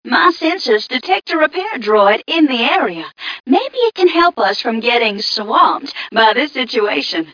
mission_voice_m3ca007.mp3